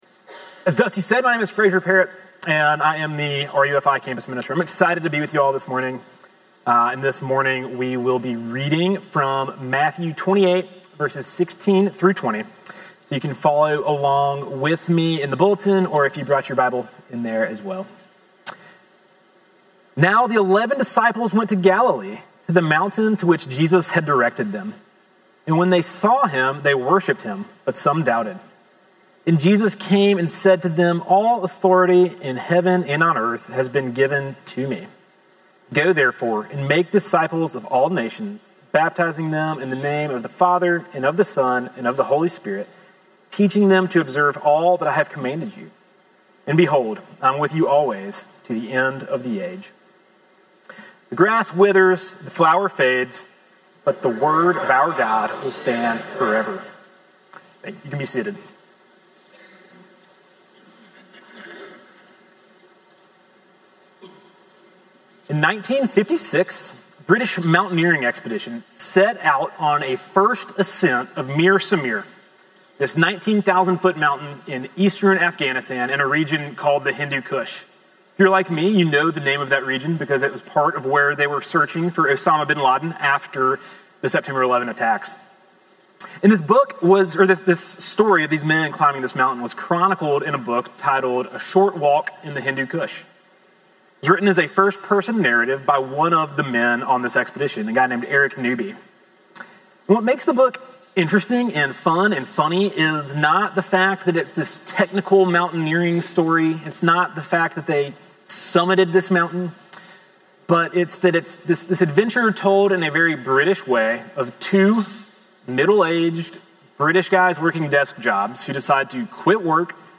Sermon Audio from Sunday